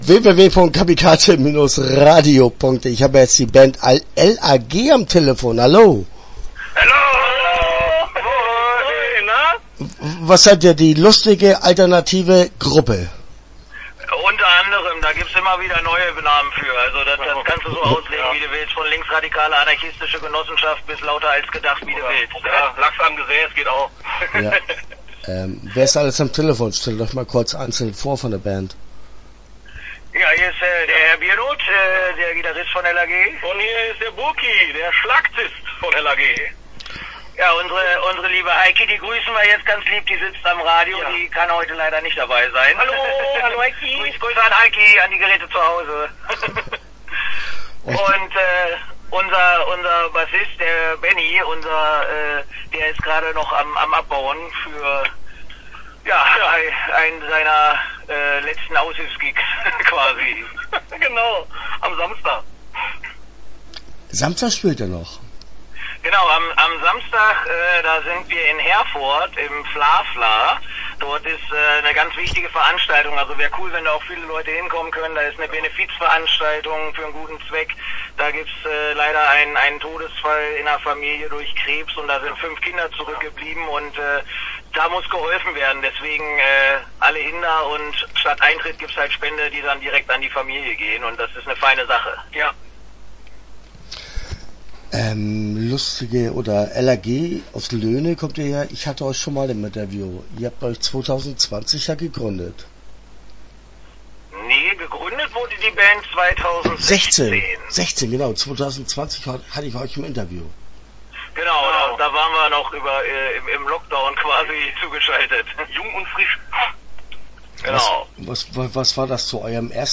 L.A.G. - Interview - Teil 1. (13:12)